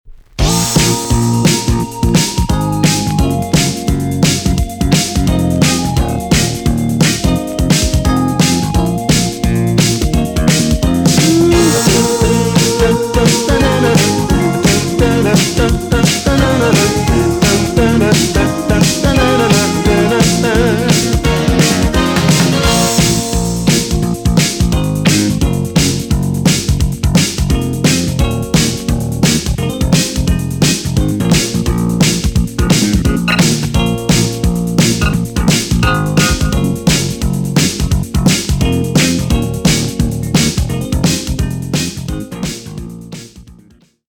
B.SIDE Inst
EX 音はキレイです。